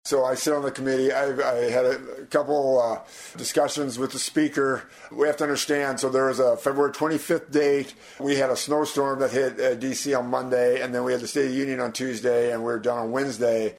THAT VOTE DID NOT HAPPEN, BUT IOWA 4TH DISTRICT CONGRESSMAN RANDY FEENSTRA SAYS THERE WERE REASONS WHY THE VOTE DID NOT TAKE PLACE: